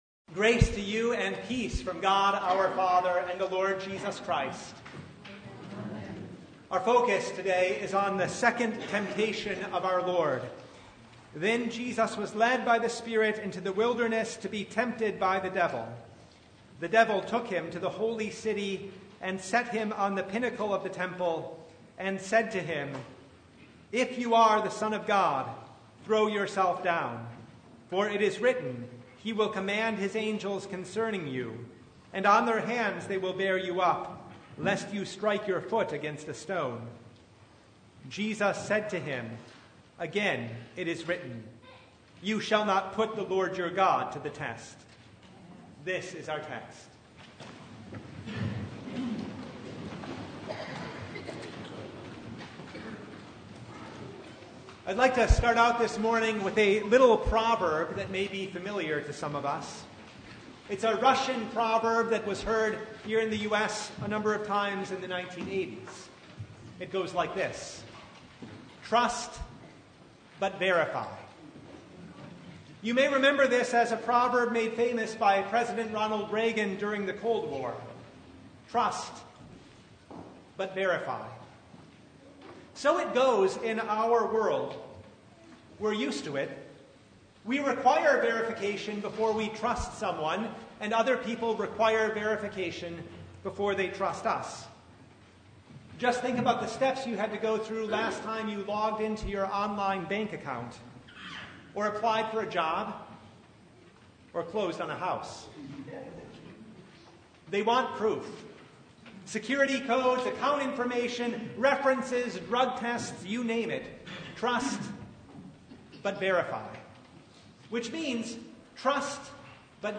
Matthew 4:1-11 Service Type: Sunday Bible Text